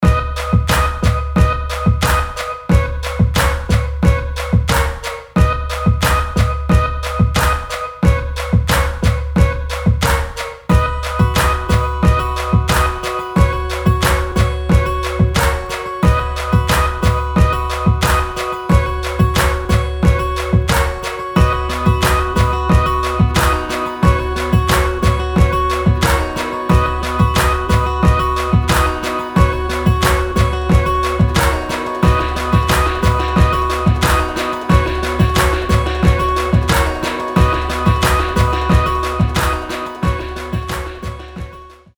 • Качество: 320, Stereo
гитара
мелодичные
без слов
красивая мелодия
Acoustic